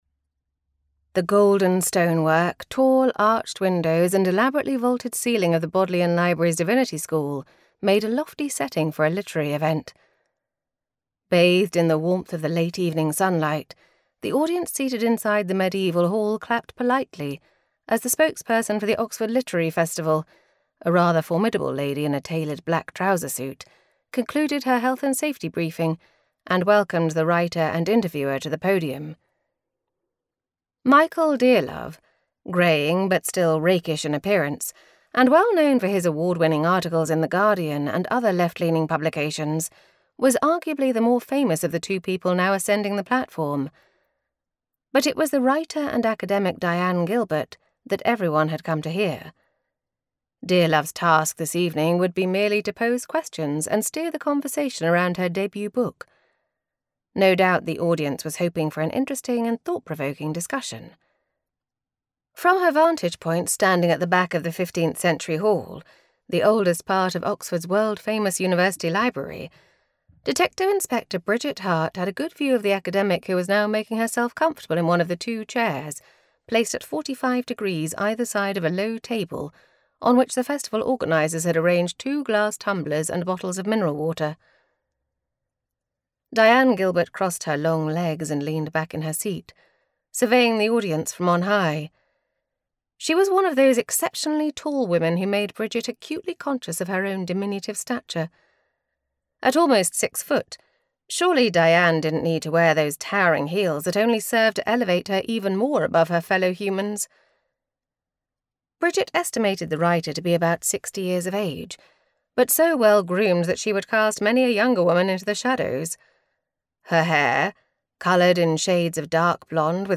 Preface to Murder Audiobook Extract